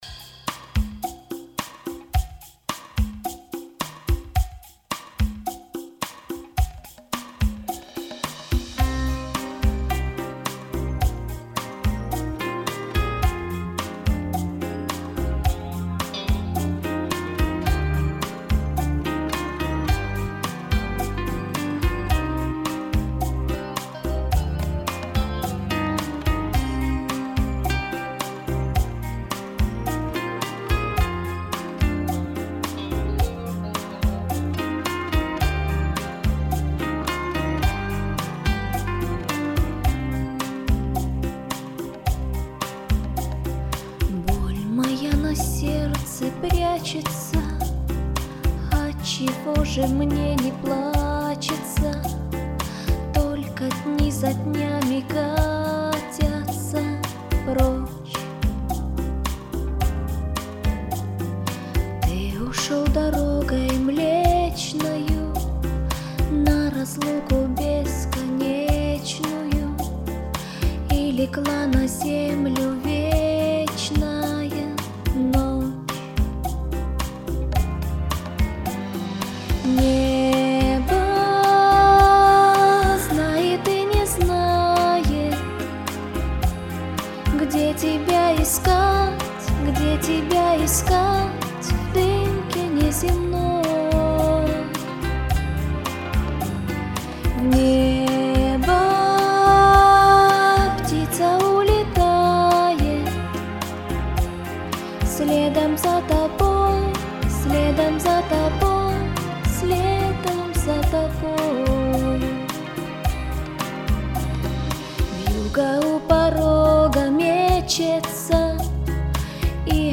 с караошной записью на плеер он-лайн